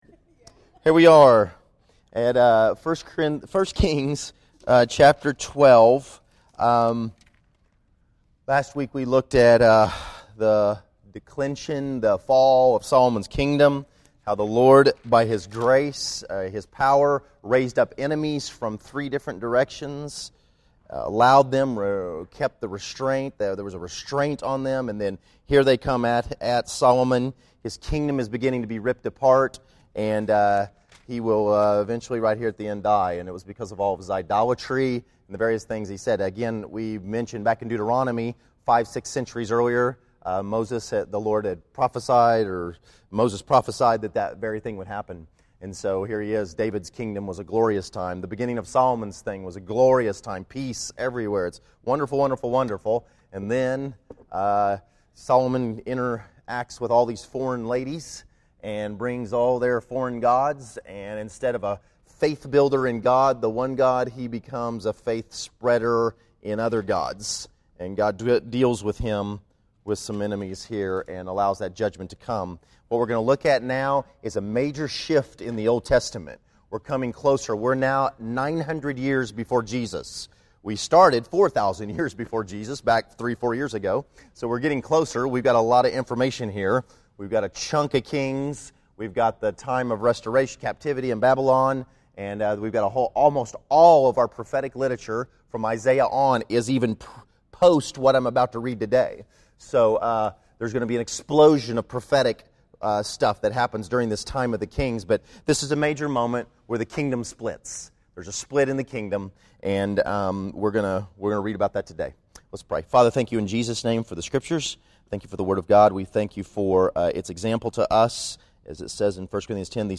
1 Kings 12 July 10, 2011 Category: Sunday School | Location: El Dorado Back to the Resource Library Israels rebellion against Rehoboam and division of the kingdom is used in contrast to the power that is present in unity in the New Covenant Church.